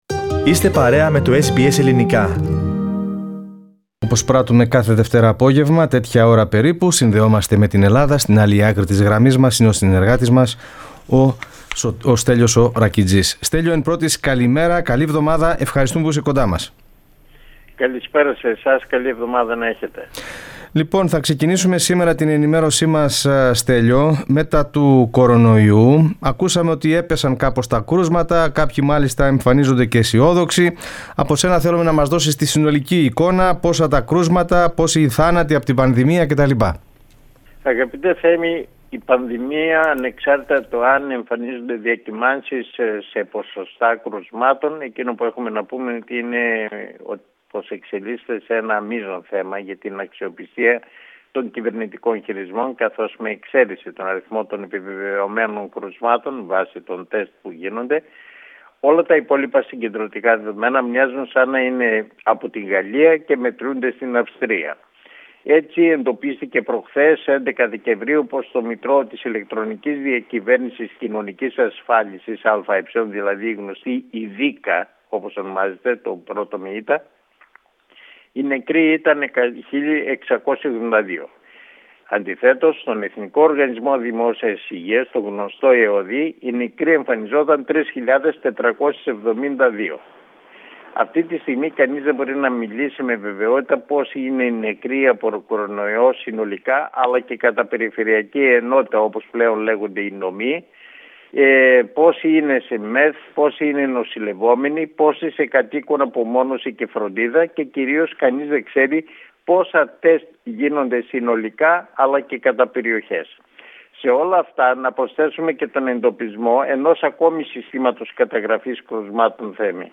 Πατήστε Play στην κεντρική φωτογραφία για να ακούσετε το podcast με την εβδομαδιαία ανταπόκριση από την Ελλάδα Διαβάστε ακόμη...